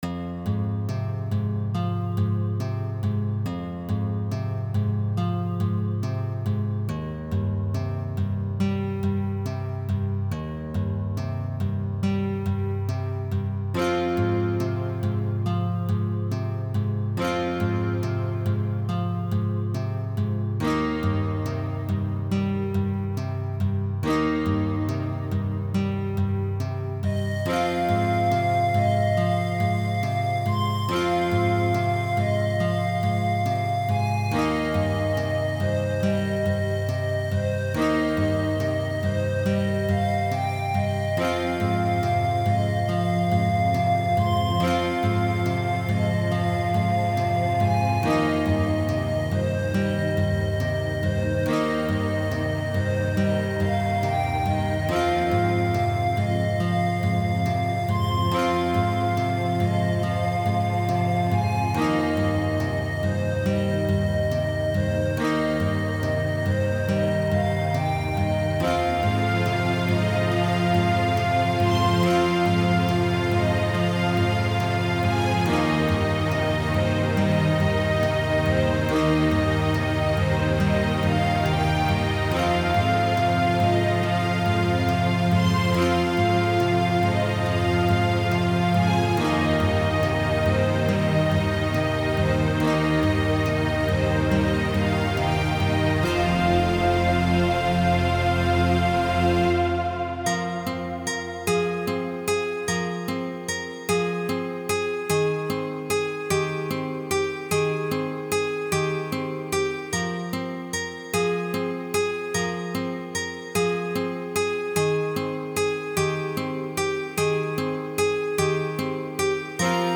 Light middleage theme.